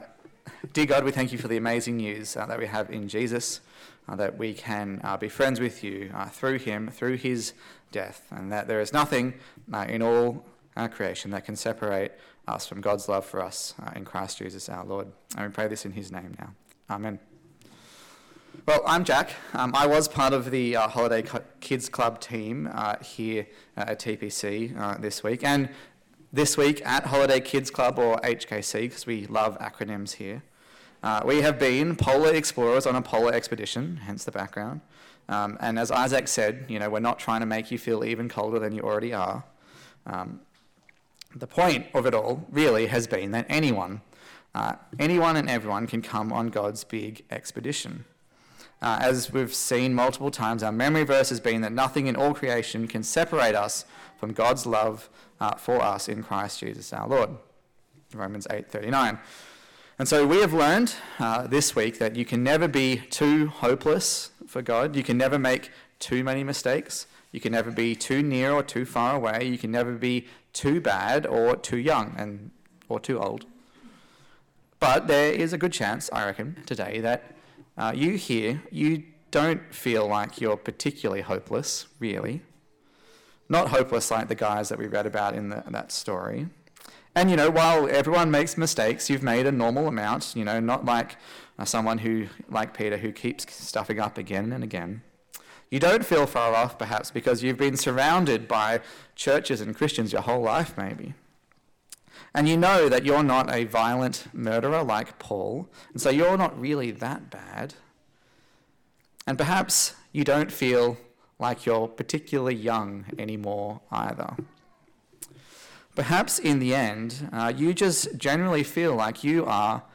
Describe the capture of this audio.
Passage: Acts 16:11-15 Service Type: Sunday Service